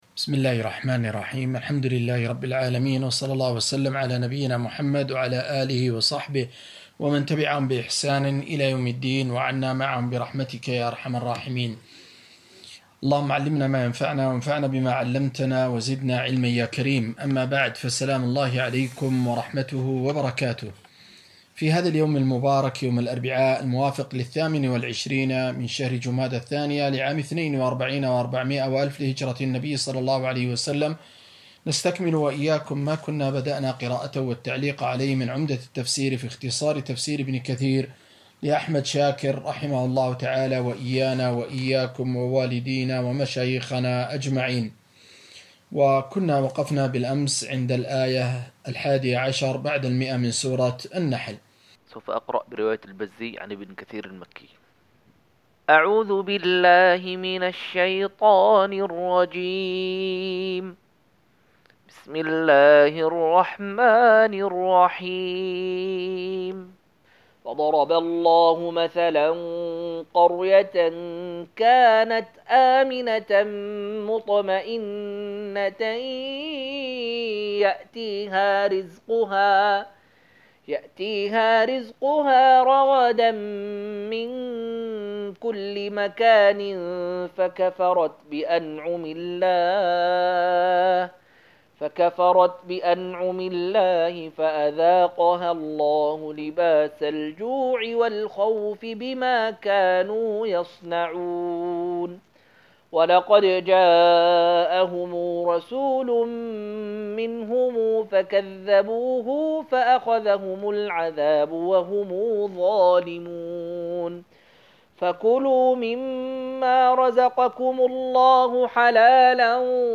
258- عمدة التفسير عن الحافظ ابن كثير رحمه الله للعلامة أحمد شاكر رحمه الله – قراءة وتعليق –